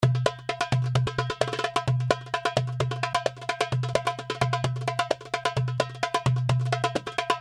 The darbuka is in stereo mode, that means that The darbuka was recorded twice.(beat in the left speaker). The darbuka loops are at : (70,75,80,85,90,95,100,105,110,115,120,125,130,135,140,145,150,155) All the loops are dry, with no room reverb. The darbuka loops are in mix mode(no mastering,no over compressing). There is only light and perfect analog EQ and light compression, giving you the The opportunity to shape the loops in the sound you like in your song.
This Bundle contains (444) Real Darbuka Loops in “malfuf”, “baladi” and “wahda” rhythm.
The darbuka was recorded with vintage neumann u87 in a dry room by a professional Darbuka player.